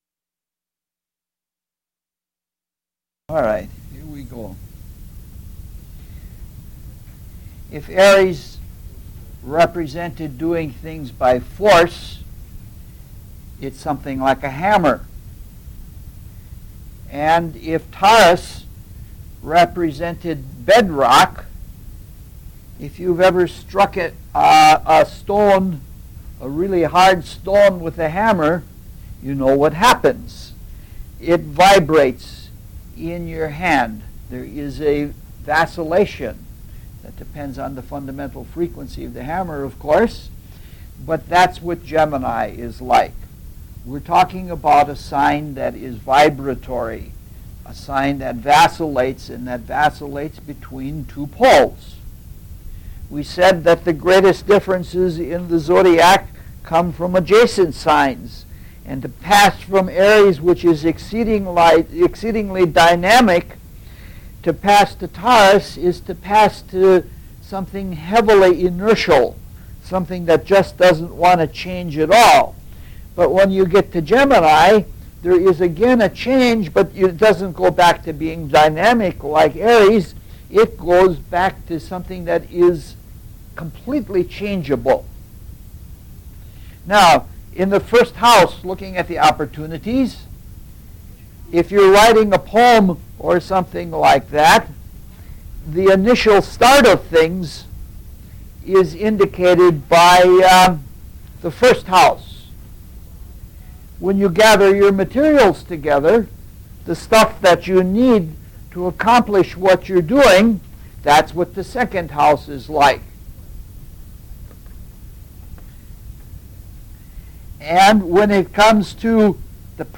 LECTURES ON ASTROLOGICAL ELEMENTS
LECTURE SERIES